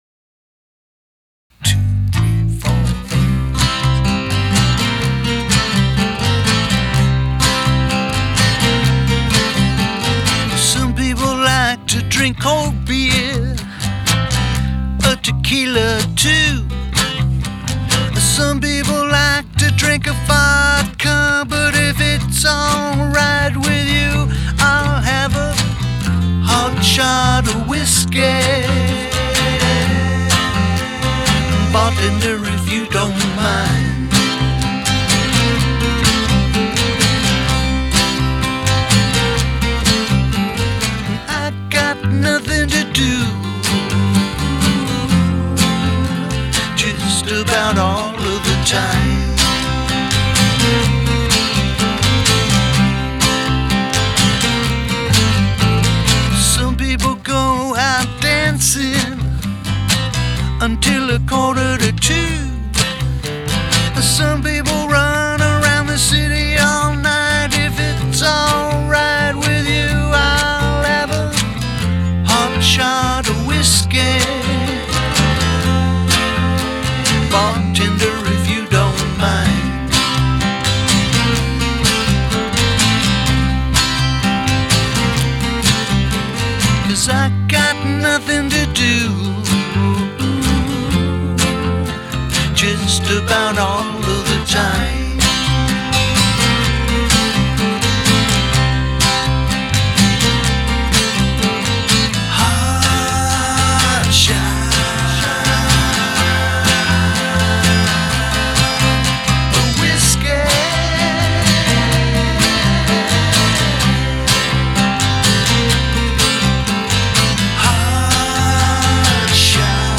ok last post on this MXL BCD1....lol jammed a short tune out using only the MXL BCD1 on vox and acoustics (bass was di).. fun mic, cheap...handles everything well. maybe my ears? but I think it sounds good. over and out. Attachments HardShot.webp 110.8 KB · Views: 269 Hard Shot A Whiskey mp3.mp3 Hard Shot A Whiskey mp3.mp3 7.4 MB · Views: 265